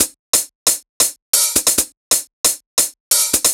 Live Hats 135bpm.wav